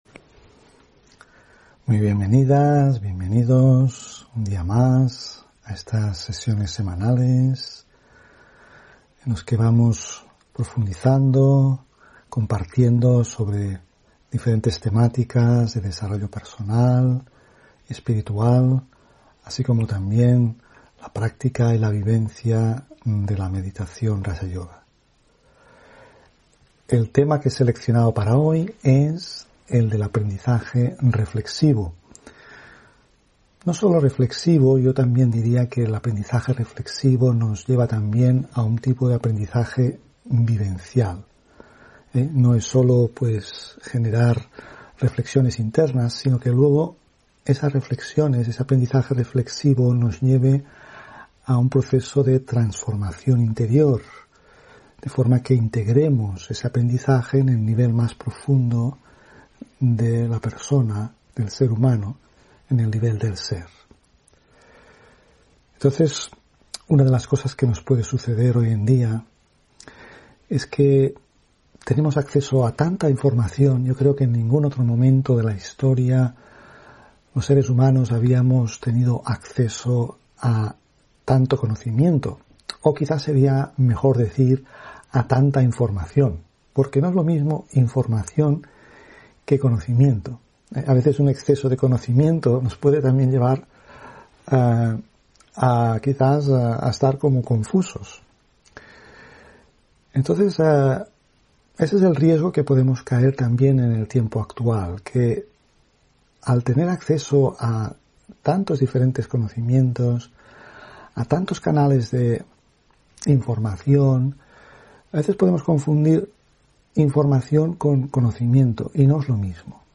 Meditación y conferencia: Creando un ambiente a través de nuestra actitud (9 Diciembre 2 ...